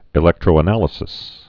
(ĭ-lĕktrō-ə-nălĭ-sĭs)